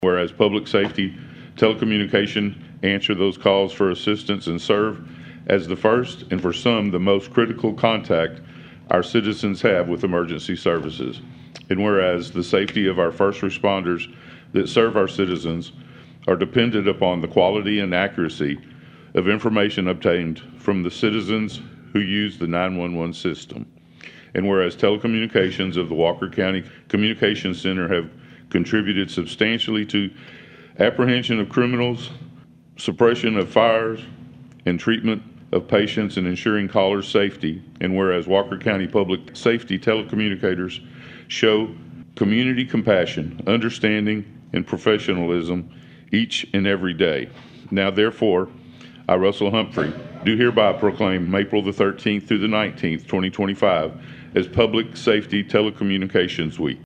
At last night’s regular session of the Huntsville City Council, the city saluted those who work in the public safety telecommunications field, and particularly those in Walker County.
Mayor Russell Humphrey passed along a proclamation to those in attendance.